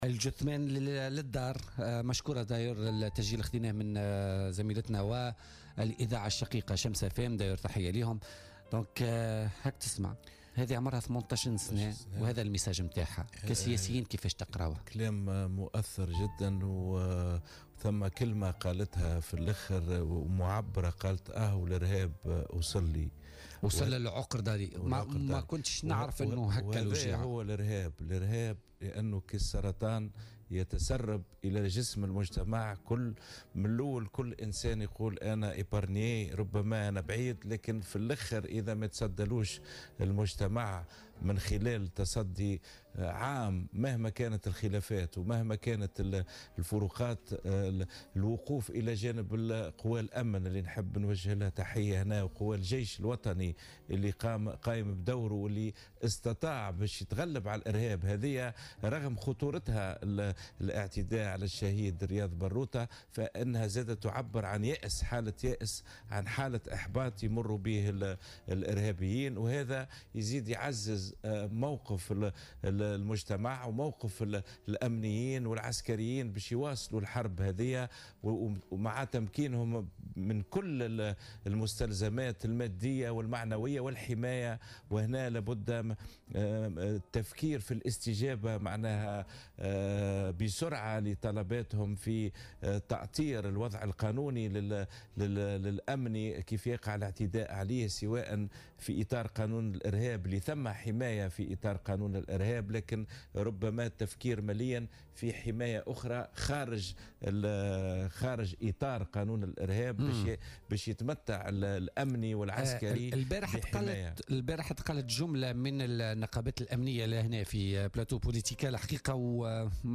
قال رضا بلحاج منسق حزب تونس أولا والقيادي السابق في حركة نداء تونس ضيف بولتيكا اليوم الجمعة 03 نوفمبر 2017 إن الإرهاب كالسرطان يتسلل إلى جسم المجتمع تدريجيا ويمكن أن يصل إلى أي شخص في أي وقت إذا لم يتصدى له المجتمع من خلال رؤية عامة مهما كان اختلاف وجهات النظر على حد قوله.